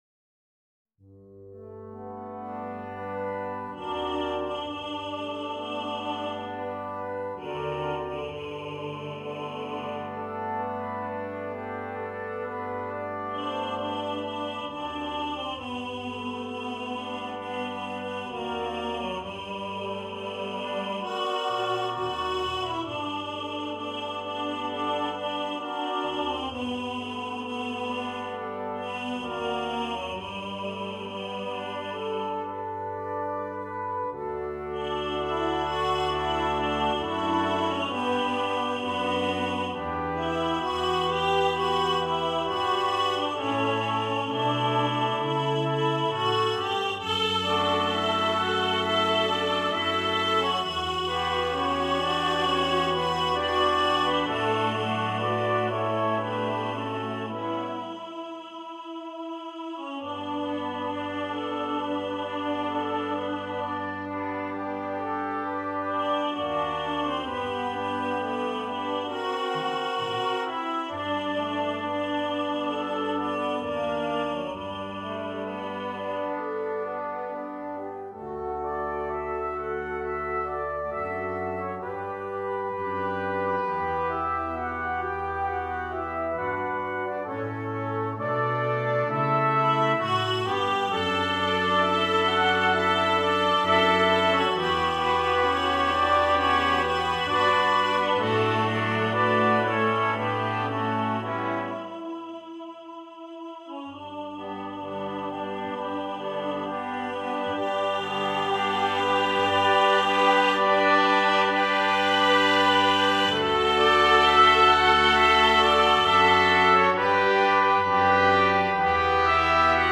Brass Quintet and Tenor
This arrangement is in the original key.